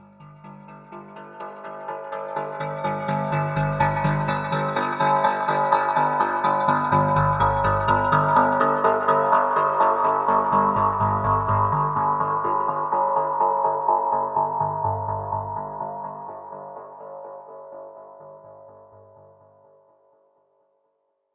Cyber-Punk-Transition.mp3